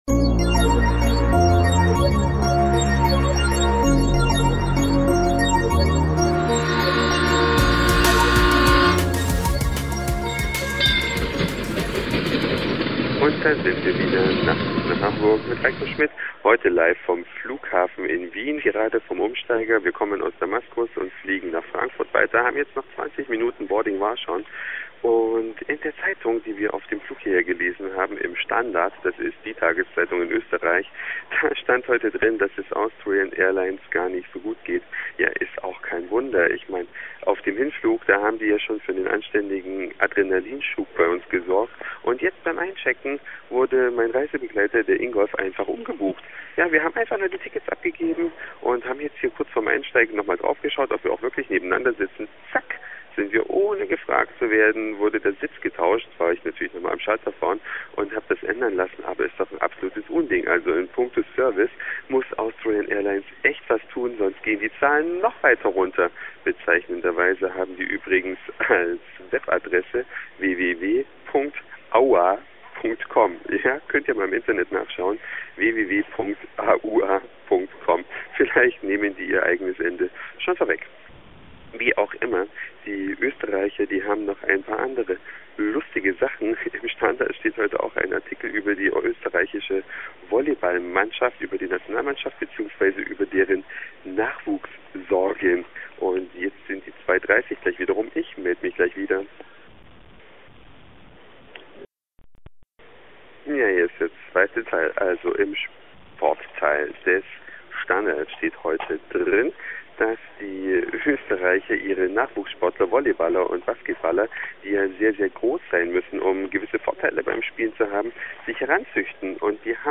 Heute kommt der Podcast aus Wien und